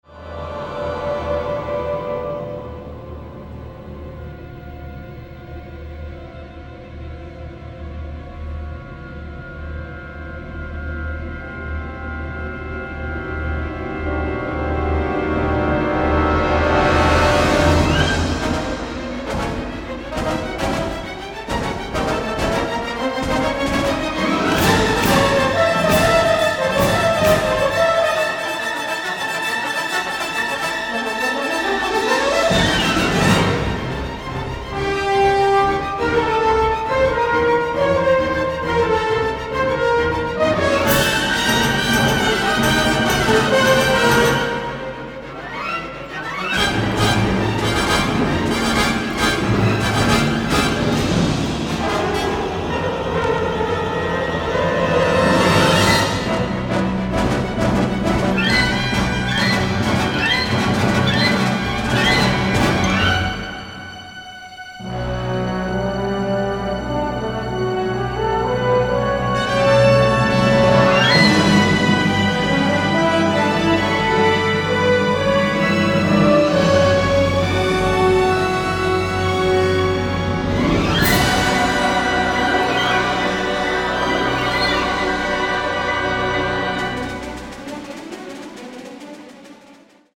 powerhouse, original orchestral material